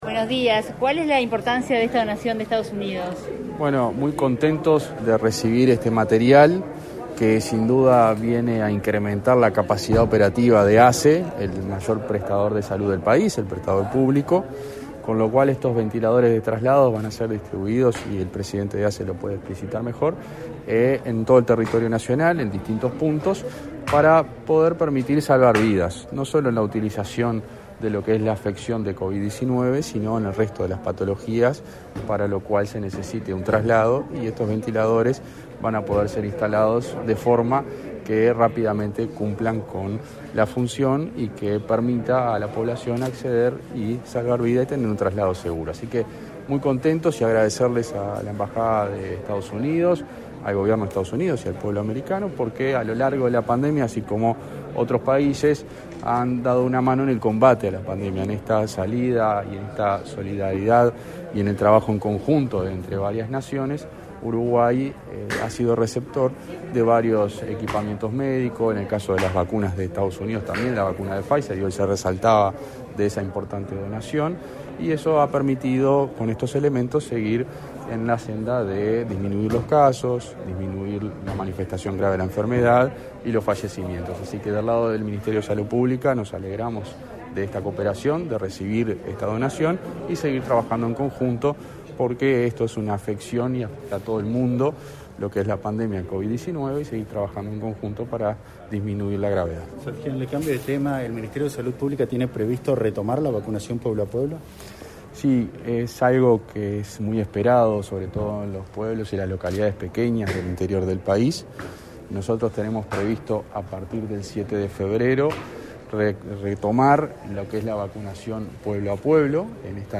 Declaraciones a la prensa del subsecretario de Salud Pública, José Luis Satdjian
Declaraciones a la prensa del subsecretario de Salud Pública, José Luis Satdjian 27/01/2022 Compartir Facebook X Copiar enlace WhatsApp LinkedIn Tras la entrega de 12 ventiladores médicos transportables del Sistema Nacional de Emergencias (Sinae) a la Administración de los Servicios de Salud del Estado (ASSE), este 27 de enero, el subsecretario José Luis Satdjian efectuó declaraciones a la prensa.